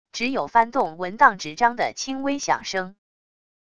只有翻动文档纸张的轻微响声wav音频